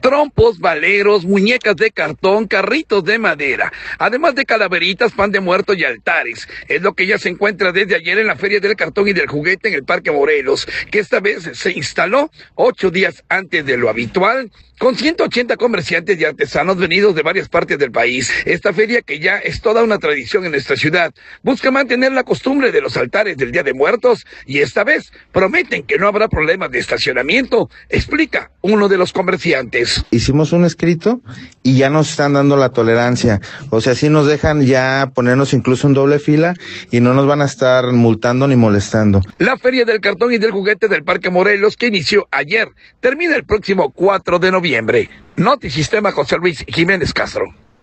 audio Trompos, baleros, muñecas de cartón, carritos de madera, además de calaveritas, pan de muerto y altares, es lo que ya se encuentra desde ayer en la Feria del Cartón y del Juguete en el Parque Morelos, que esta vez se instaló 8 días antes de lo habitual. Con 180 comerciantes y artesanos venidos de varias partes del país, esta feria, que ya es toda una tradición en nuestra ciudad, busca mantener la costumbre de los altares del Día de Muertos, y esta vez prometen que no habrá problemas de estacionamiento, explica uno de los comerciantes.